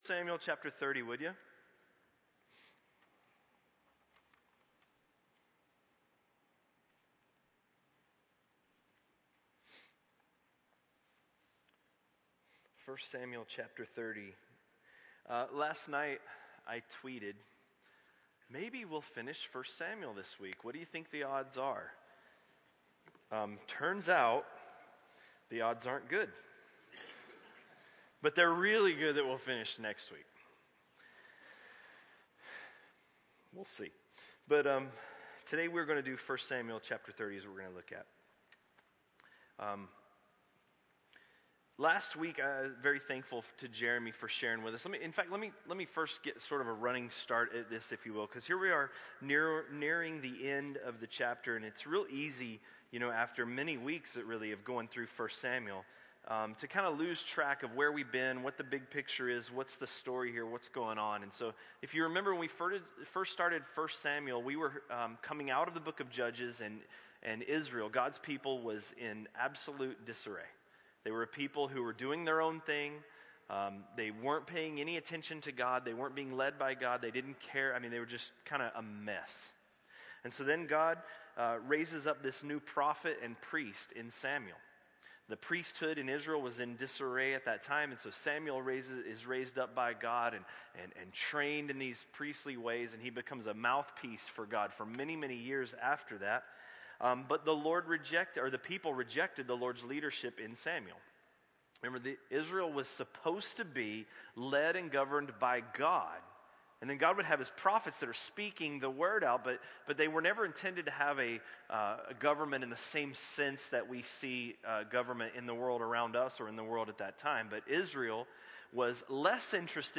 A message from the series "1 Samuel." 1 Samuel 30